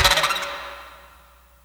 LATININECHO.wav